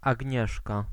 Ääntäminen
Ääntäminen Paris: IPA: [a.ɲɛs] France (Île-de-France): IPA: /a.ɲɛs/ Haettu sana löytyi näillä lähdekielillä: ranska Käännös Ääninäyte 1.